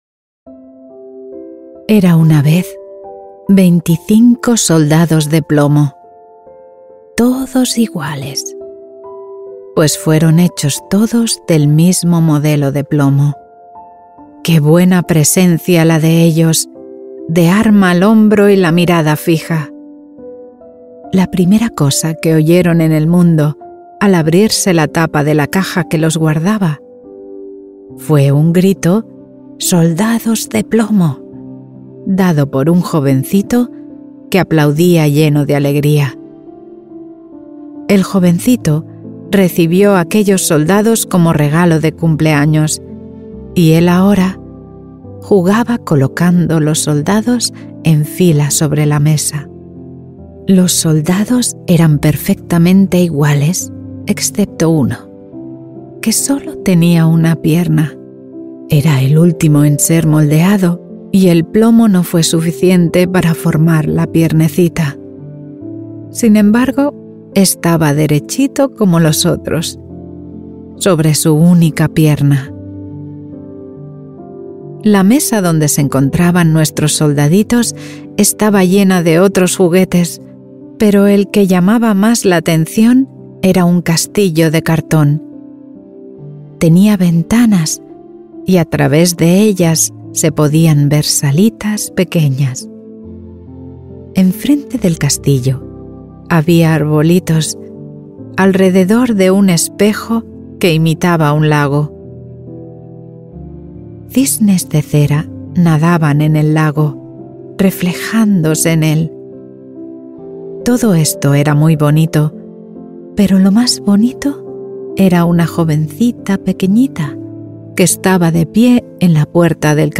Cold Wind Through an Abandoned Village
Before you sink into the quiet moments of Sleep Deeply, know this first: all advertisements are placed gently at the very beginning of each episode so nothing interrupts your rest, your breath, or the fragile moment when your mind finally loosens its grip.